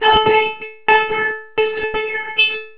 In this example, a recording of whispered speech is processed through a highly resonant filter, and real-time MIDI control is used to create polyphony, trigger the playback, and set the center frequencies of the filters.
Input heard through filter (26k)
demo-filtered.au